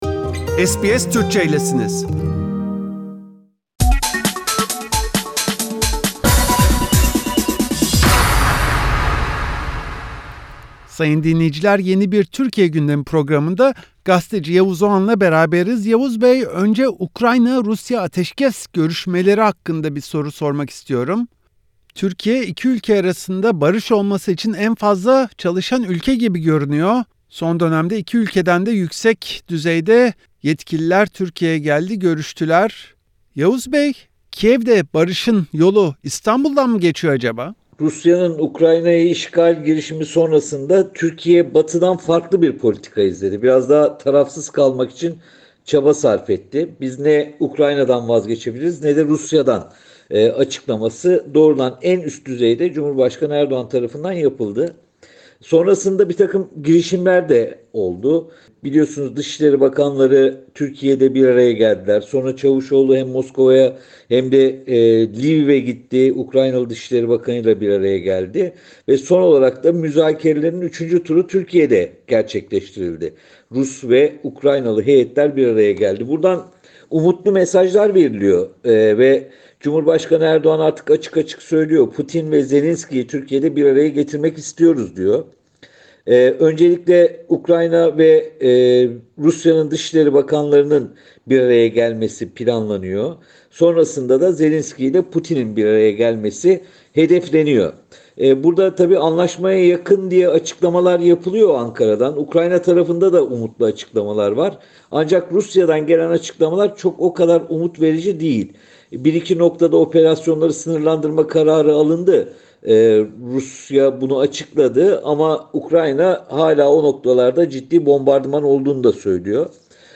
Gazeteci Yavuz Oğhan SBS Türkçe’ye yaptığı değerlendirmede, Ukrayna’da barış için iki taraf arasında mekik dokuyan bir tek Türkiye’nin kaldığını söylüyor.